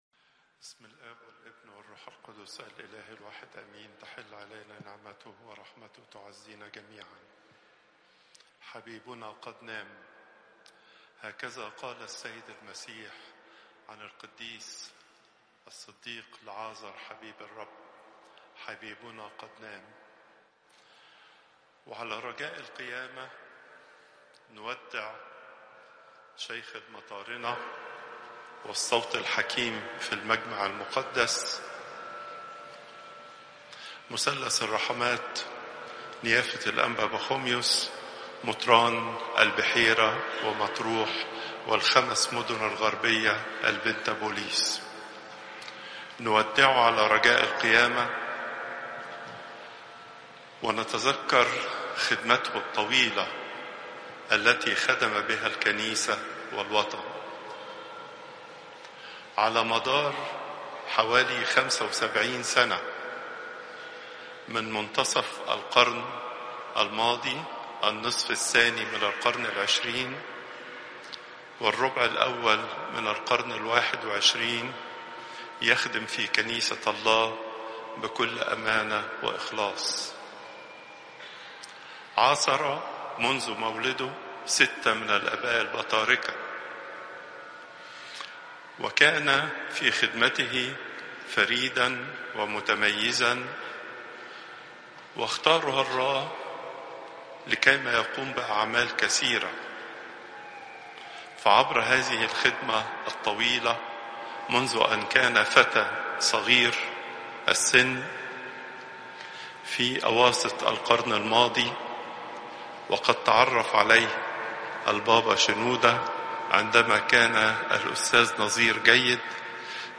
Popup Player Download Audio Pope Twadros II Sunday, 30 March 2025 32:37 Pope Tawdroes II Weekly Lecture Hits: 290